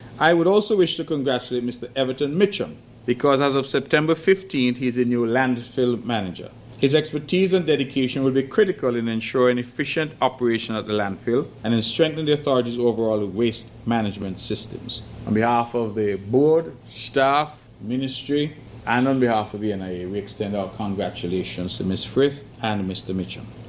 On September 30th 2025, Premier Mark Brantley held his monthly press conference where he addressed several issues affecting Nevis.